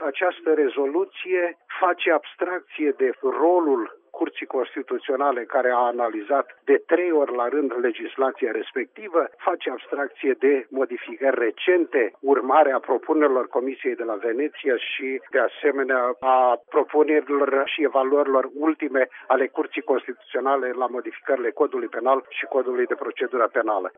Europarlamentarul PSD, Victor Boştinaru apreciază că rezoluţia adoptată de Parlamentul European este un instrument politic pentru grupurile politice europene, cu scopul de a transforma România într-un teatru de luptă electorală: